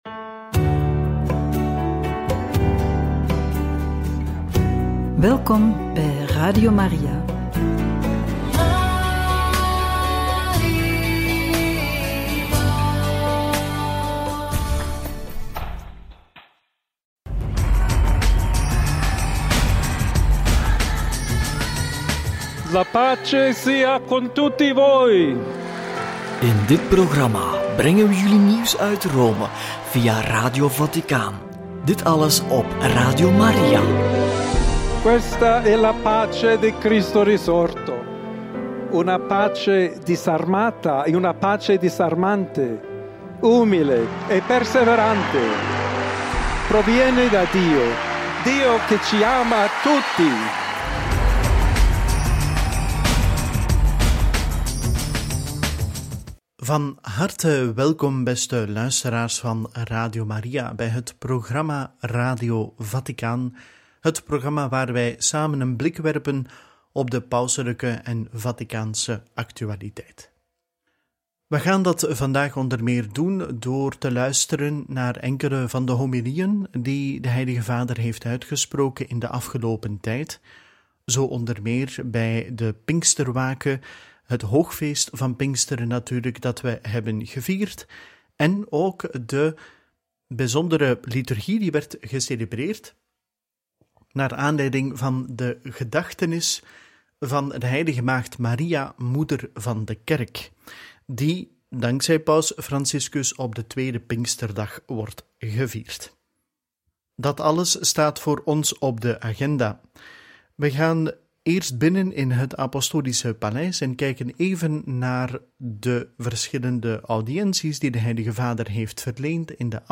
Homilie Pinksterwake – Homilie op Pinksteren – Liturgische gedachtenis van de heilige maagd Maria, Moeder van de Kerk en Jubelviering voor de Heilige Stoel – Radio Maria